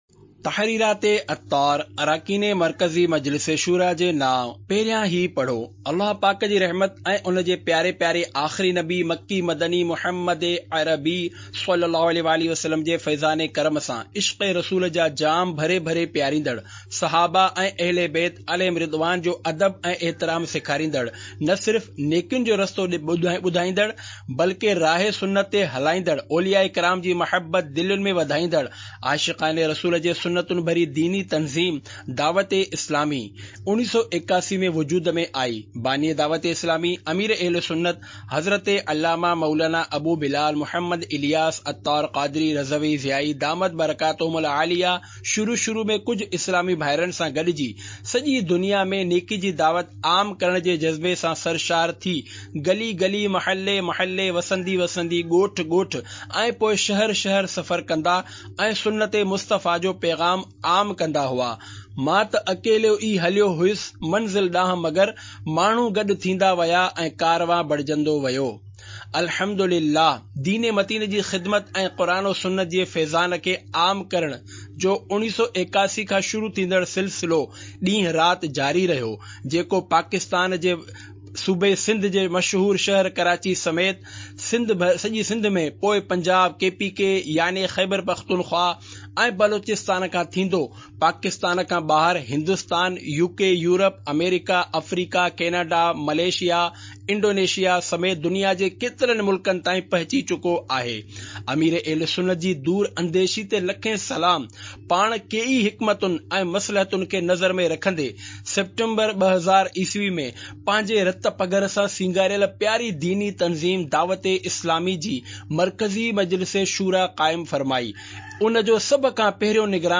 Audiobook - Tahreerat e Attar (Sindhi)